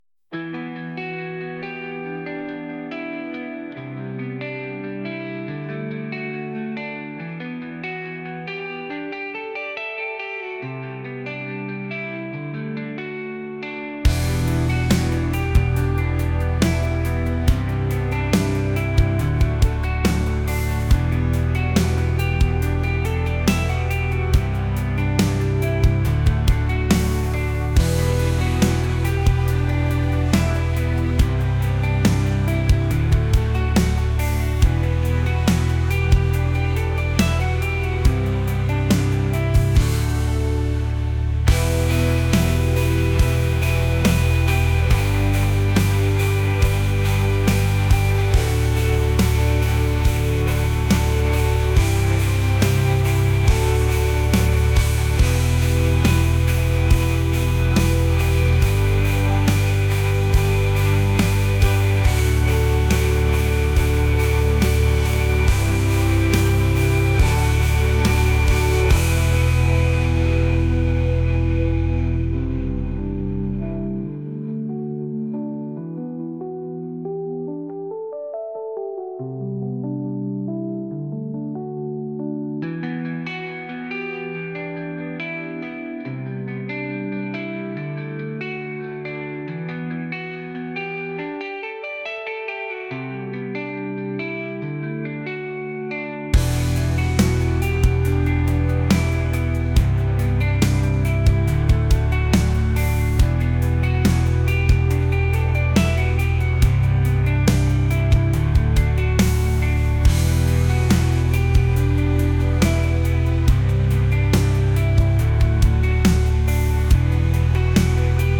indie | rock | alternative